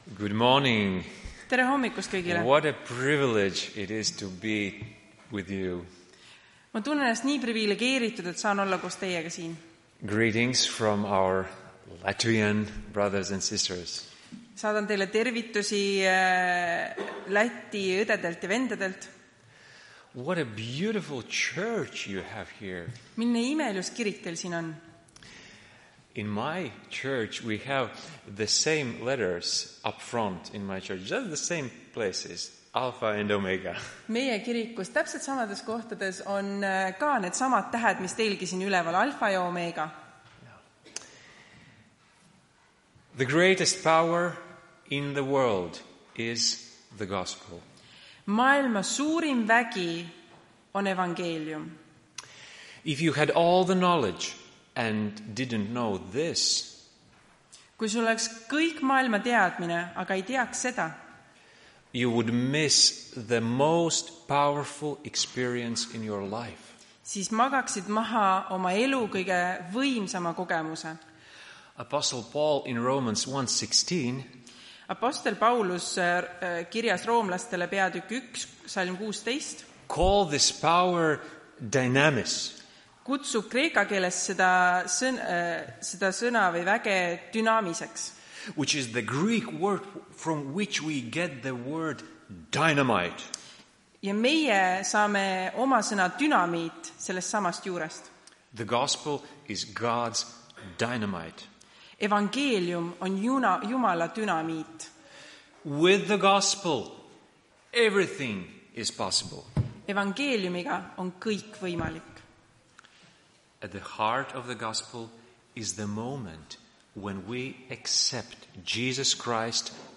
Tartu adventkoguduse 07.12.2024 hommikuse teenistuse jutluse helisalvestis.